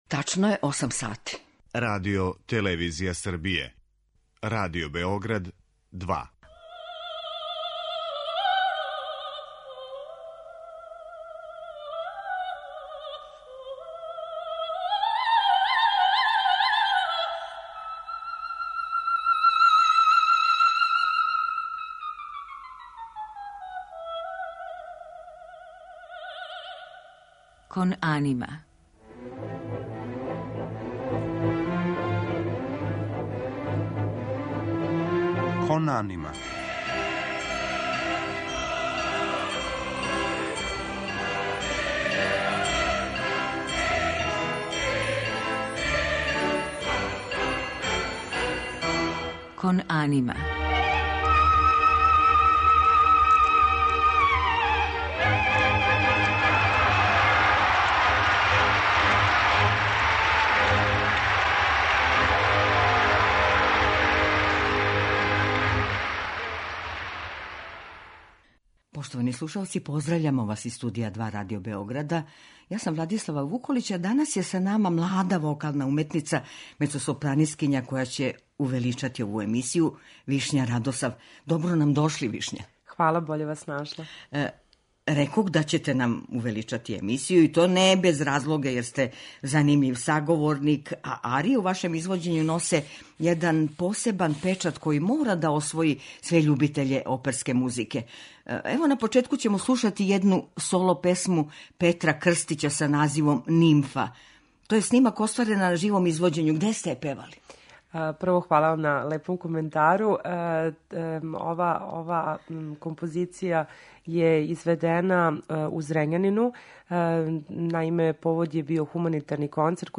У музичком делу биће емитоване арије из опера Чајковског, Моцарта и Пучинија, у њеном извођењу.